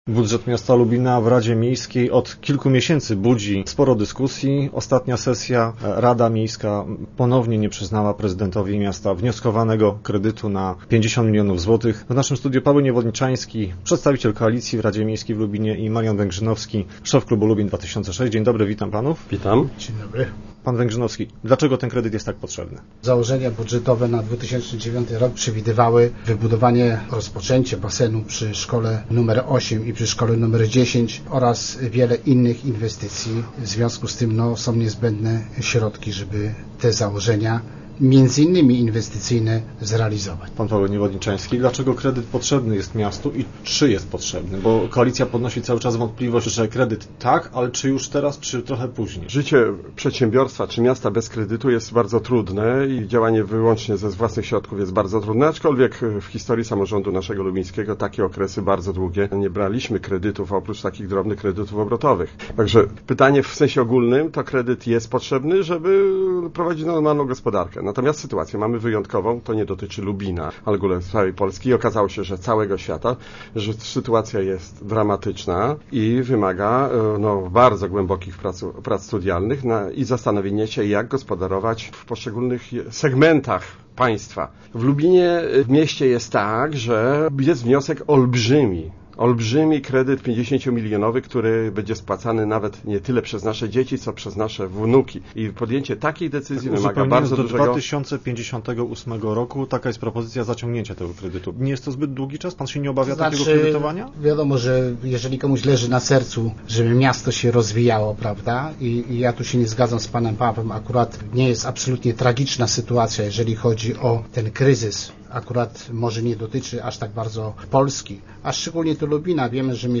Radni uważają, że jest za wcześnie na decyzję w sprawie wzięcia z banku 50 mln zł na 49 lat. Radni Paweł Niewodniczański i Marian Węgrzynowski, przedstawiali argumenty „za i przeciw” kredytowi w Rozmowach Elki.